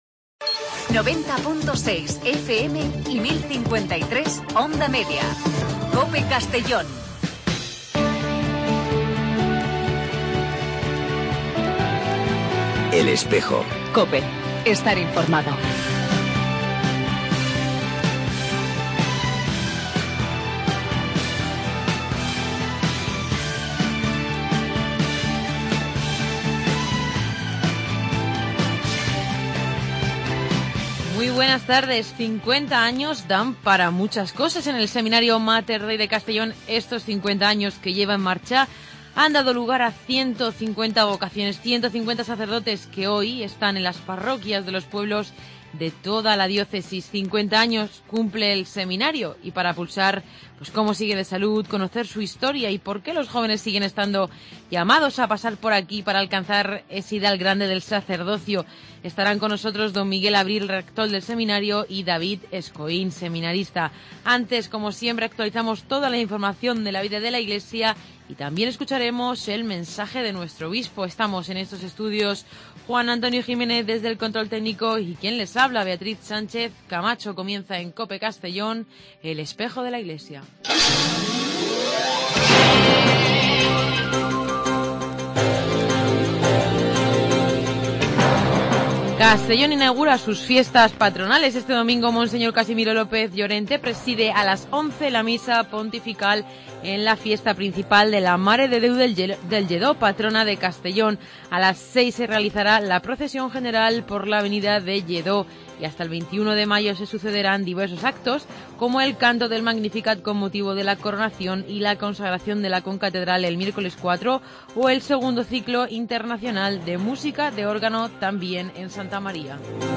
AUDIO: El programa de radio de la diócesis de Segorbe-Castellón.
Con entrevistas, información y el mensaje semanal de monseñor Casimiro López Llorente.